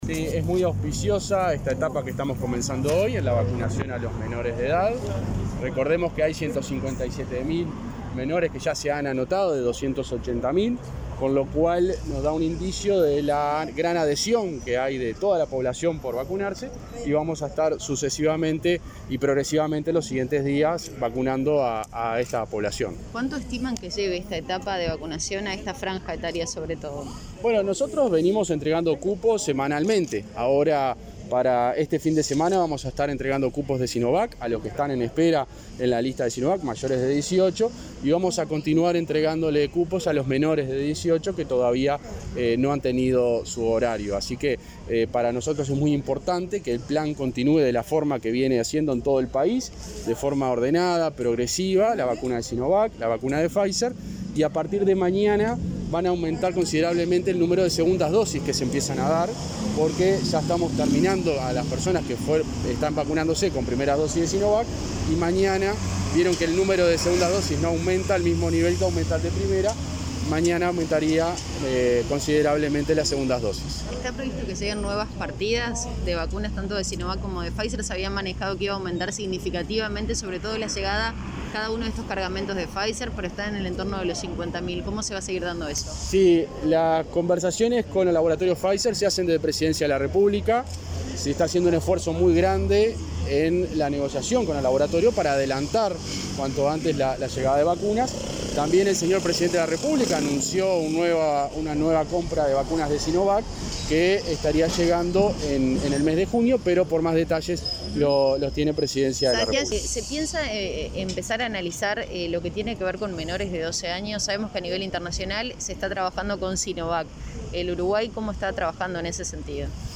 Declaraciones del subsecretario del Ministerio de Salud Pública José Luis Satdjián